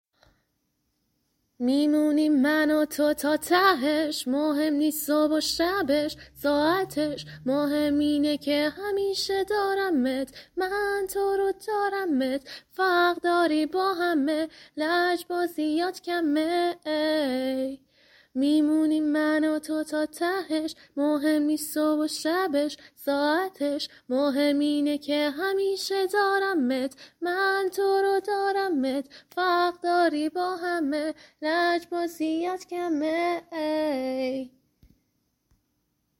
صدات خوبه و لرزش نداره